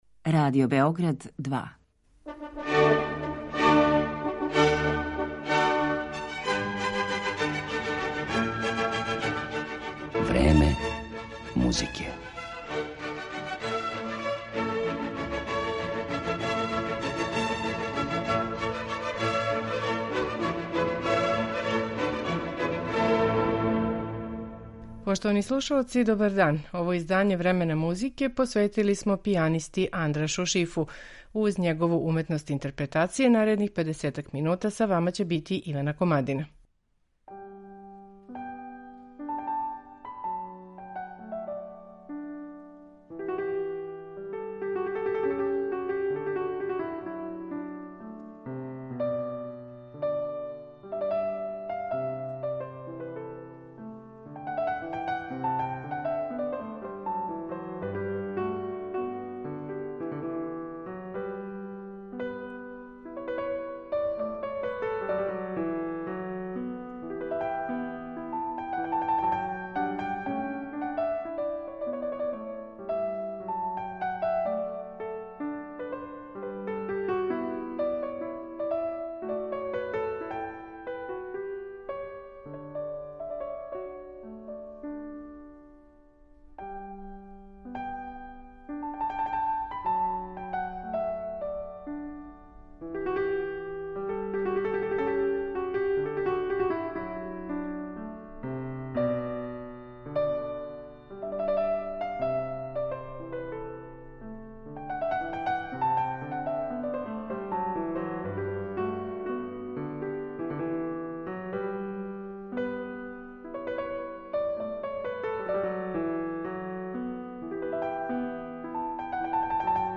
Пијаниста Андраш Шиф